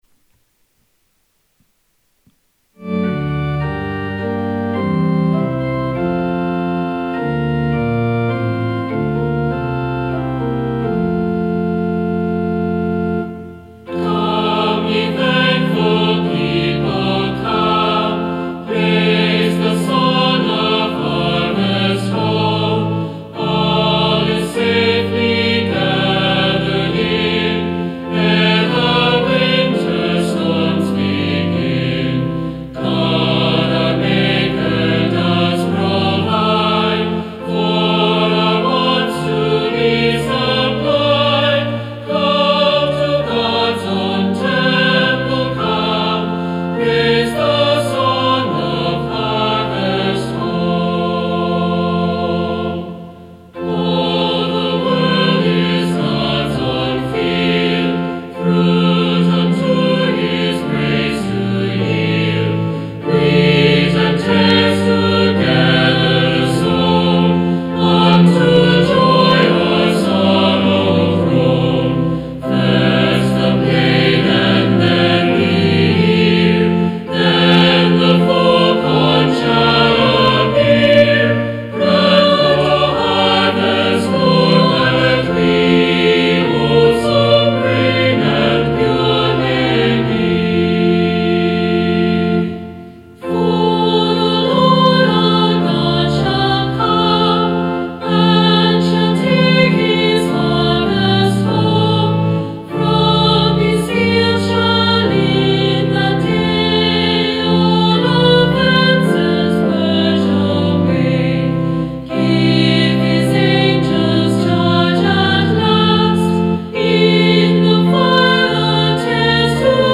Hymn: Come, Ye Thankful People Come